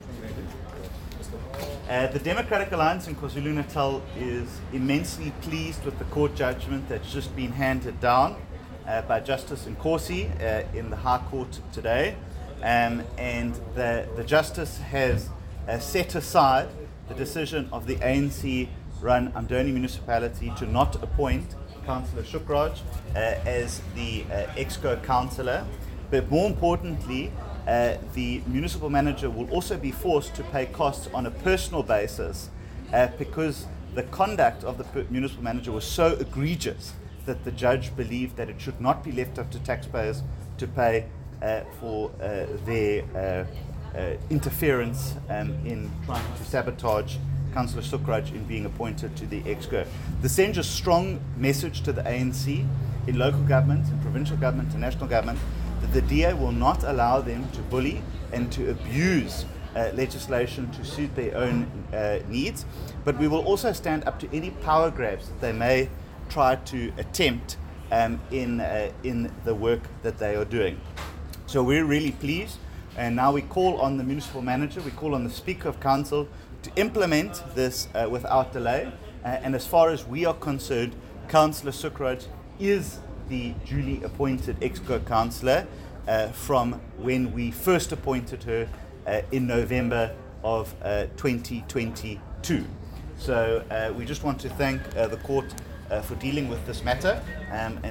soundbite by Dean Macpherson, DA KZN Chairperson.